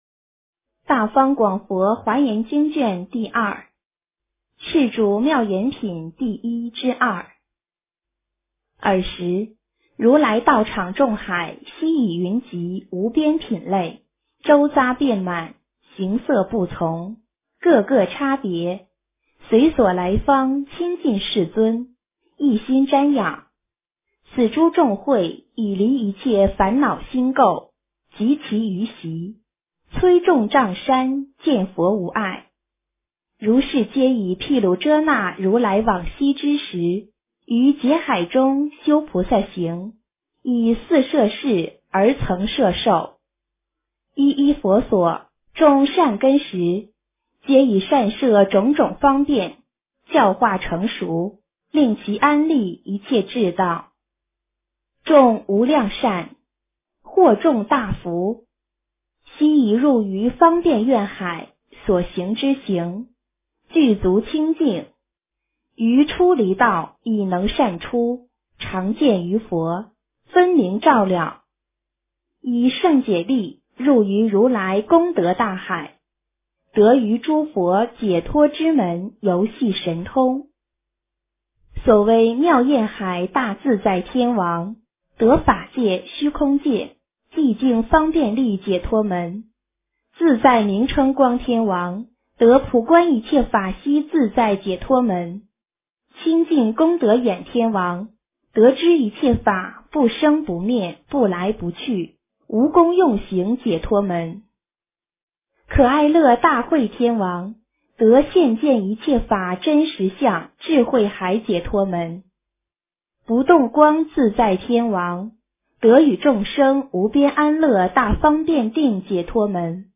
华严经02 - 诵经 - 云佛论坛